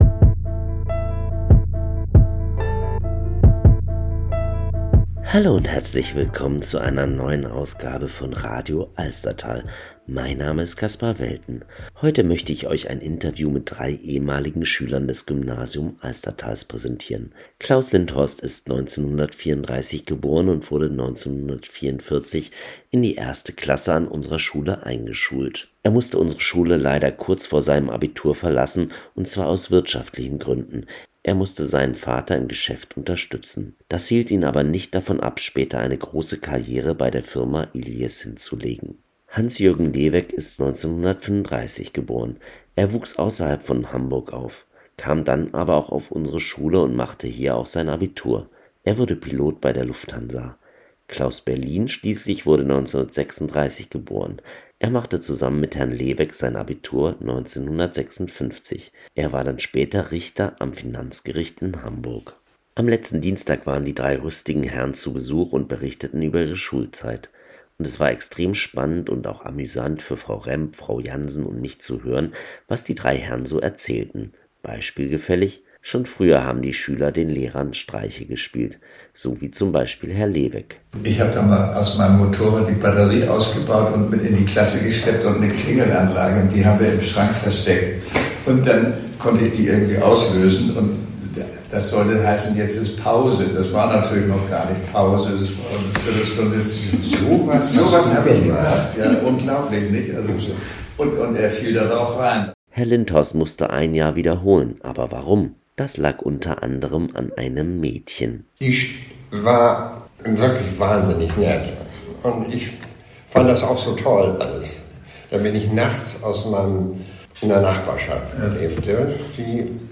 Auch nach vielen Jahrzehnten stehen die drei ehemaligen Alstertaler noch miteinander in Kontakt und waren am Mittwoch, den 17. September 2025, bei uns am Gymnasium Alstertal zu Gast.